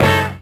HIT OLD BR07.wav